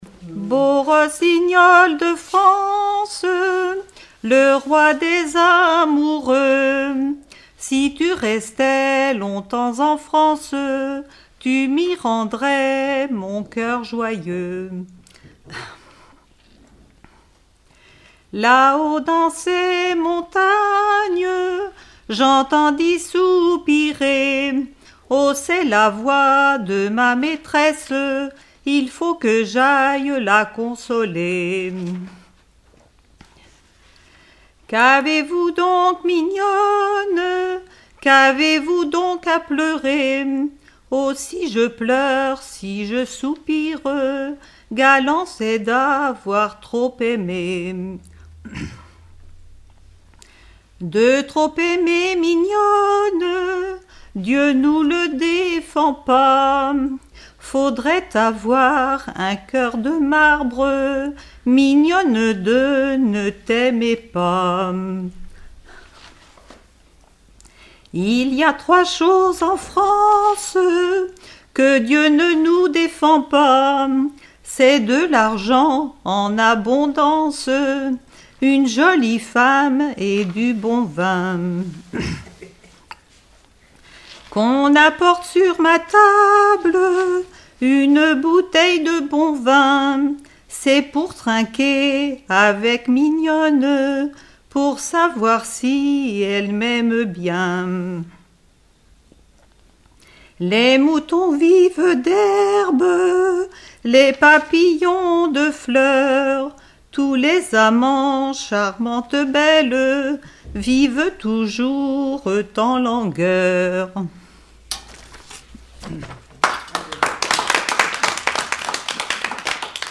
Collectif-veillée (2ème prise de son)
Pièce musicale inédite